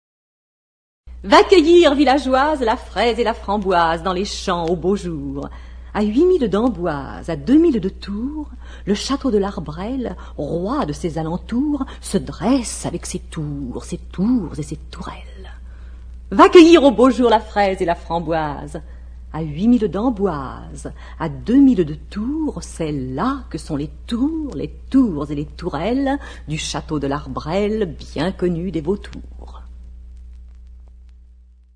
dit par Hélène PERDRIERE